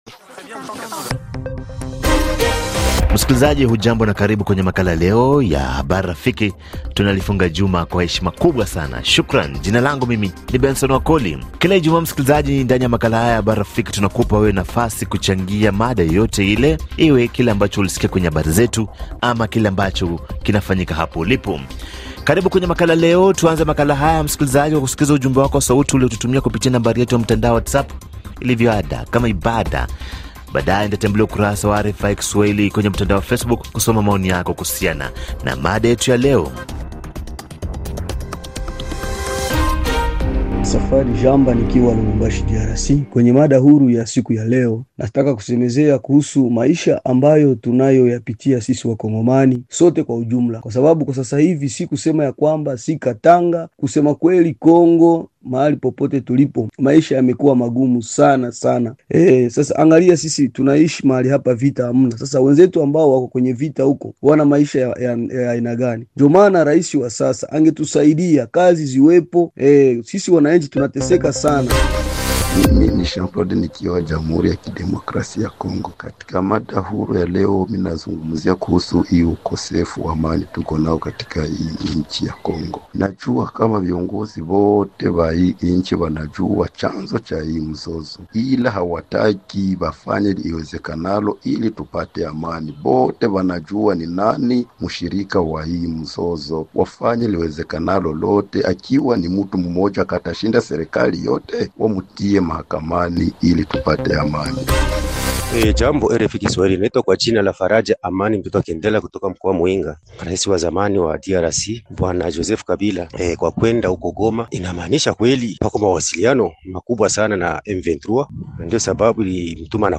Makala ya kila siku yanayompa fursa msikilizaji kutoa maoni yake juu ya habari zilizopewa uzito wa juu kwa siku husika. Msikilizaji hushiriki kwa kutuma ujumbe mfupi pamoja na kupiga simu.